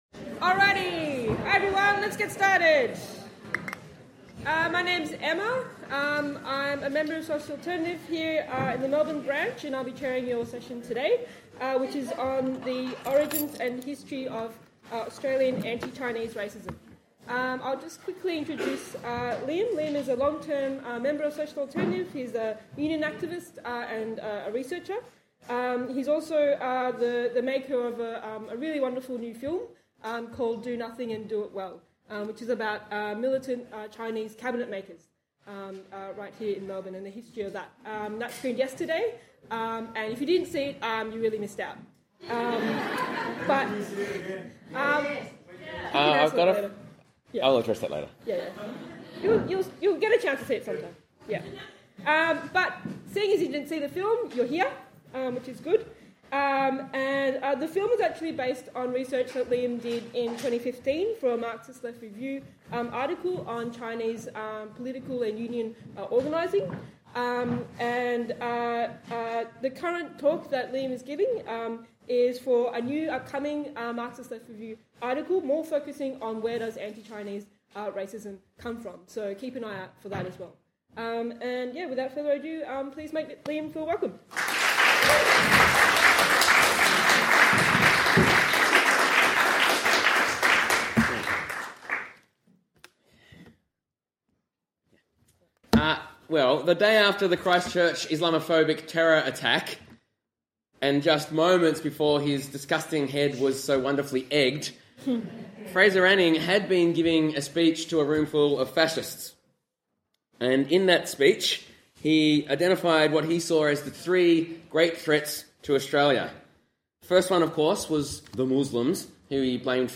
Marxism 2019